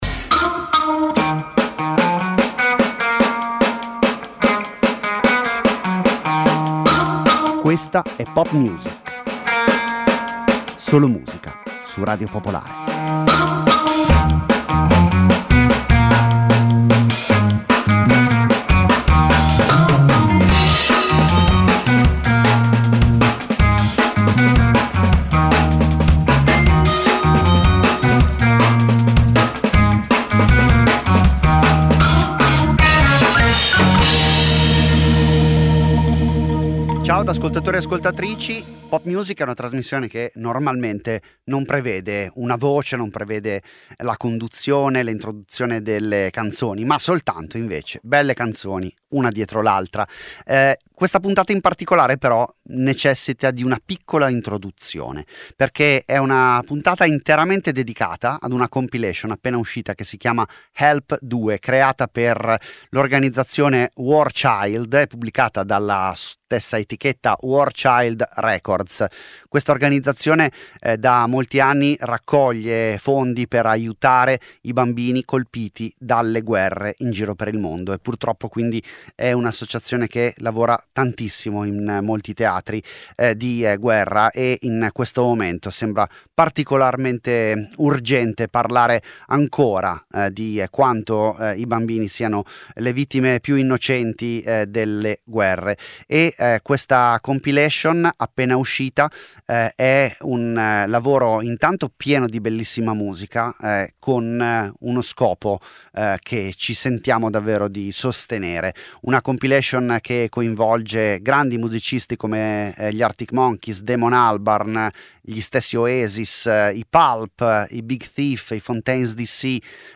Una trasmissione di musica, senza confini e senza barriere.
Senza conduttori, senza didascalie: solo e soltanto musica.